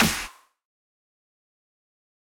MB Snare (9).wav